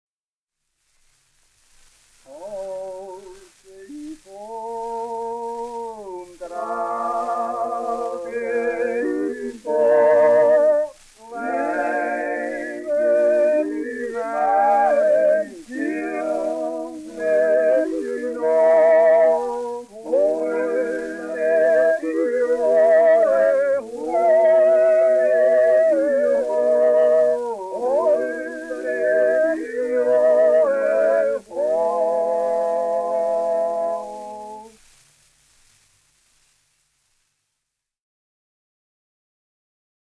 CD 1_Titel 1: WeXel oder Die Musik einer Landschaft Teil 2.1 - Das Weltliche Lied - Ungeradtaktig: Jodler und Jodler-Lied – Ungeradtaktig (E-BOOK - o:1612)